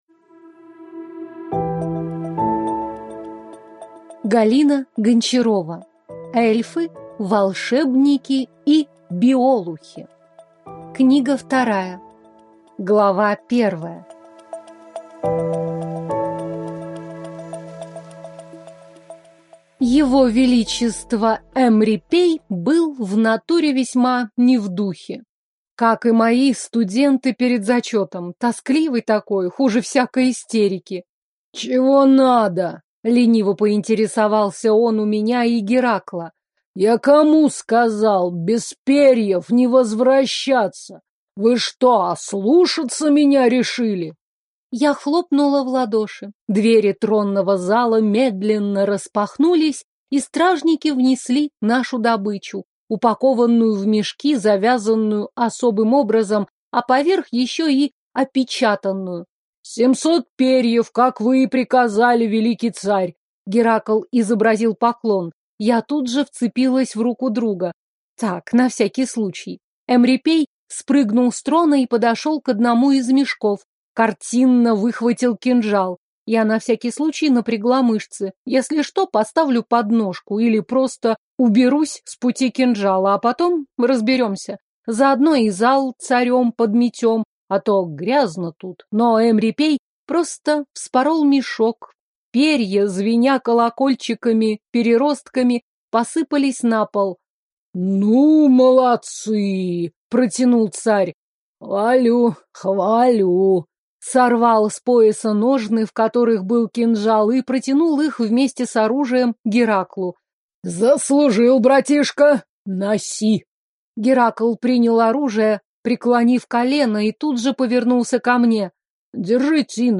Аудиокнига Эльфы, волшебники и биолухи (том 2) | Библиотека аудиокниг